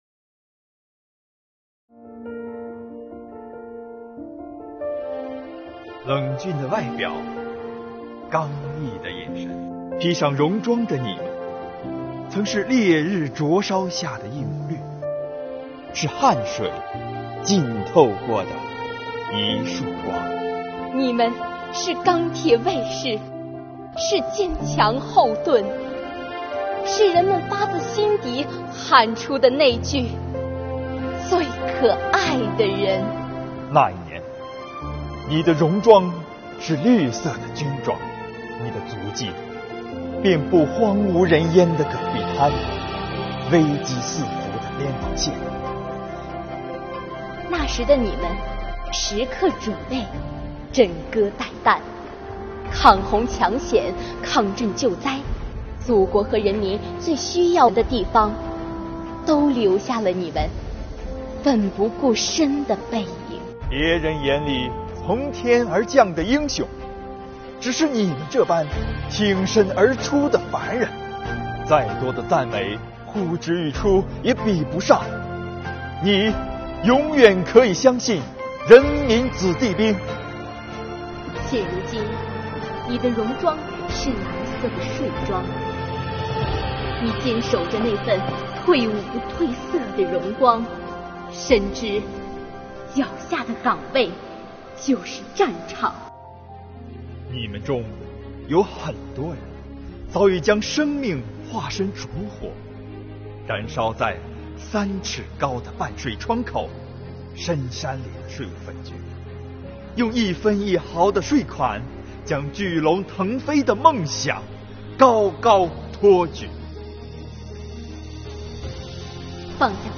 标题: 深情朗诵《戎装》，致敬税务系统军转干部！
在“八一”建军节来临之际，国家税务总局德州市税务局税务干部深情朗诵《戎装》，向退伍不褪色的税务军转干部致以崇高的敬意！